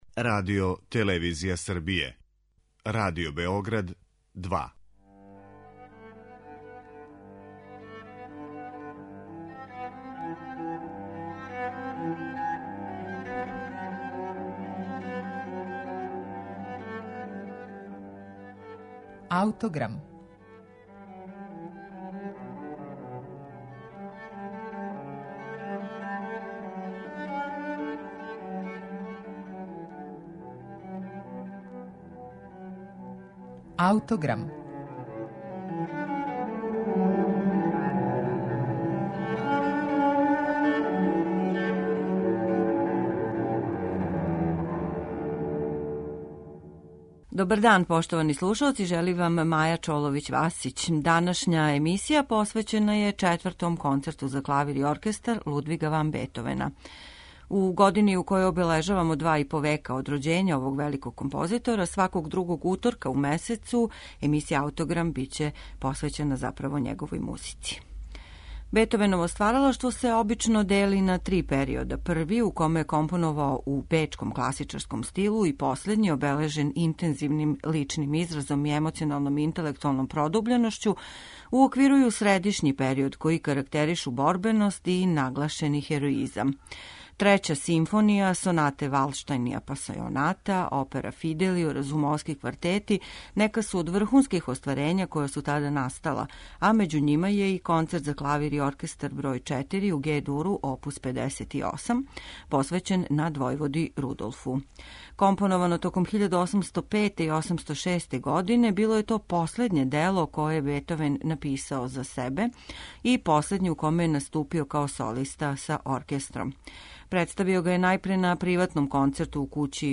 Бетовен је Концерт за клавир и оркестар бр. 4 у Ге-дуру, оп. 58 компоновао током 1805-1806. године и посветио га свом ученику, пријатељу и мецени, надвојводи Рудолфу.
Насупрот бриљантном карактеру прва три и грандиозности последњег концерта, ово дело се издваја својим лиризмом, као и уздржаним и деликатним изразом.